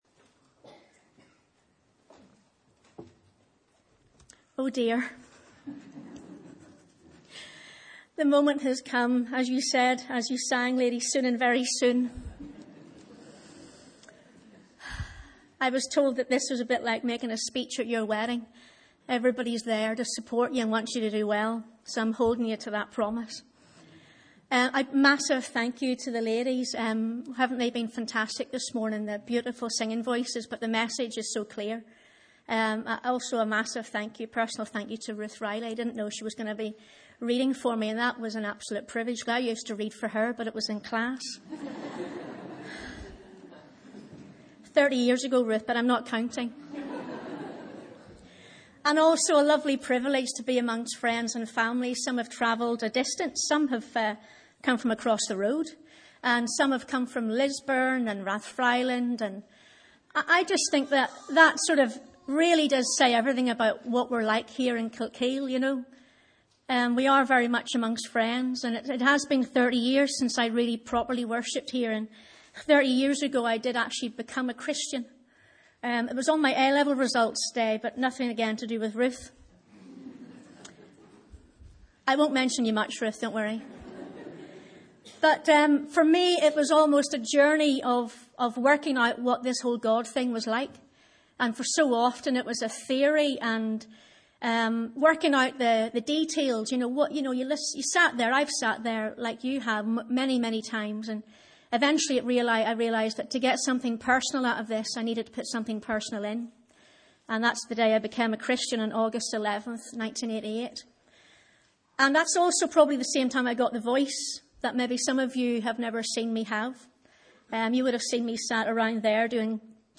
Sunday 29th April 2018 – Morning Service